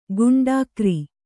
♪ guṇḍākri